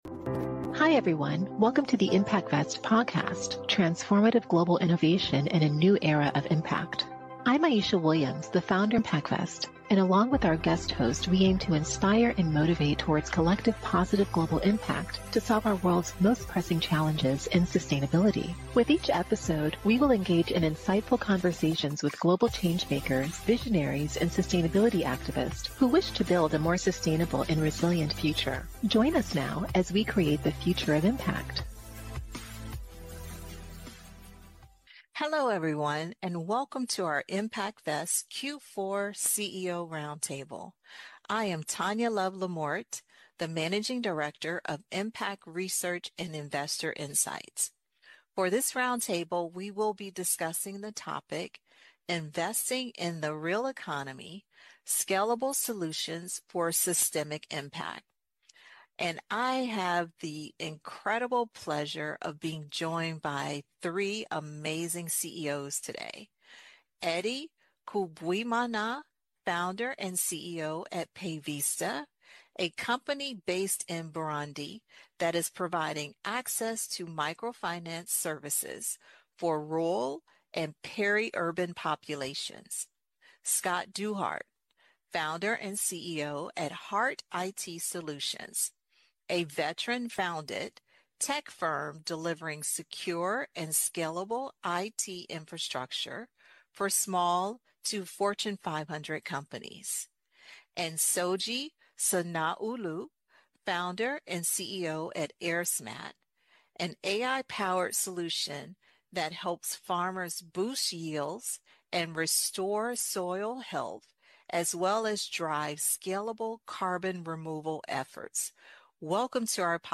Global conversations, diverse perspectives.
This CEO Roundtable, featuring an all-women panel, discusses economic transformation and sustainability.